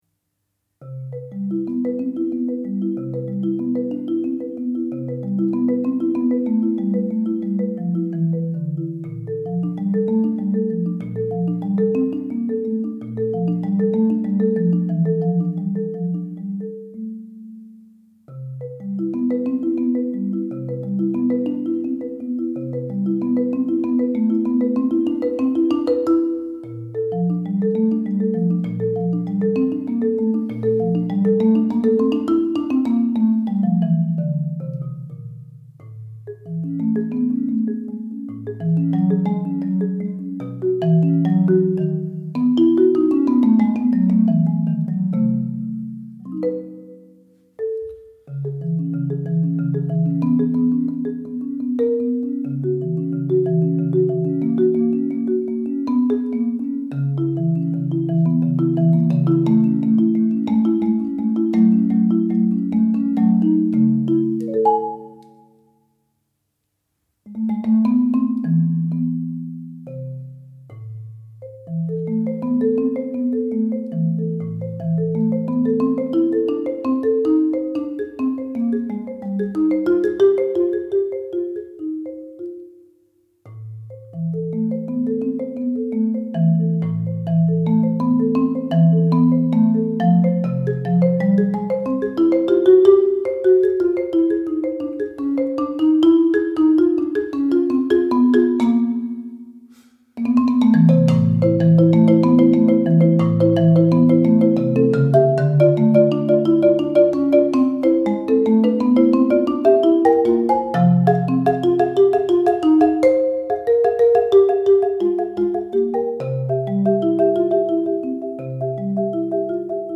für Marimba Solo
cantabel am Instrument auszudrücken